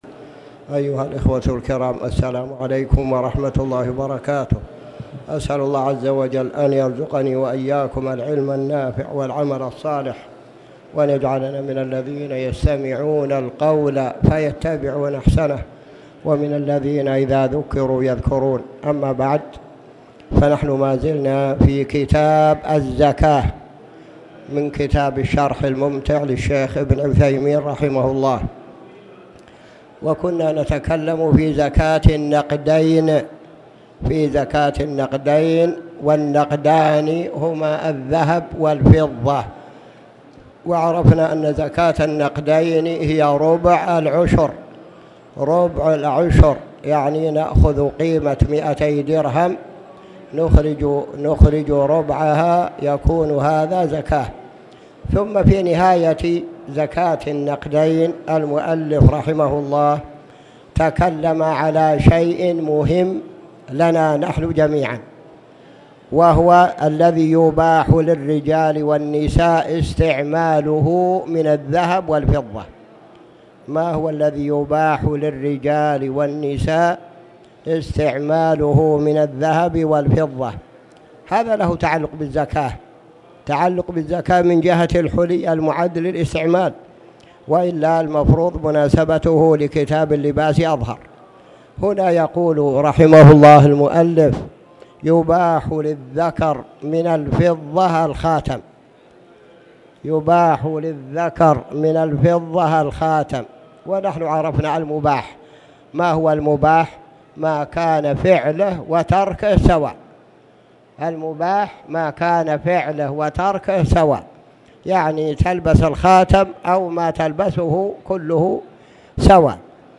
تاريخ النشر ١٩ جمادى الآخرة ١٤٣٨ هـ المكان: المسجد الحرام الشيخ